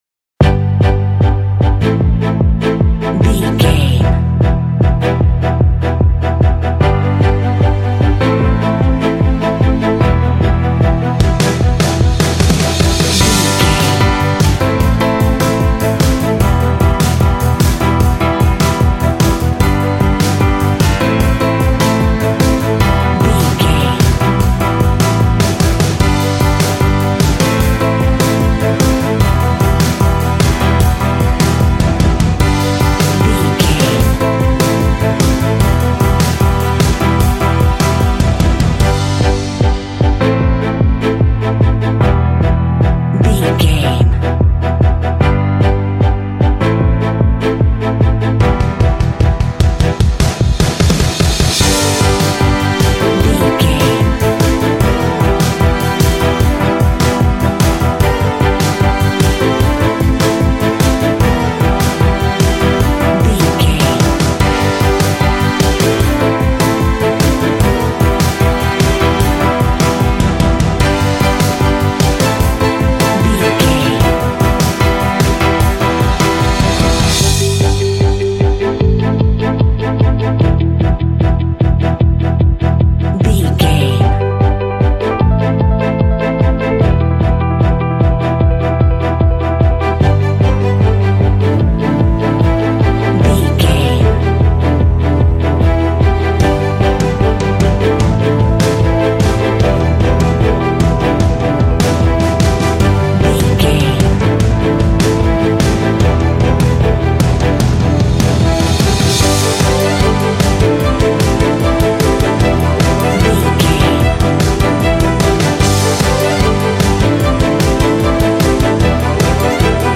Uplifting
Aeolian/Minor
driving
bright
hopeful
elegant
strings
electric guitar
piano
drums
indie
alternative rock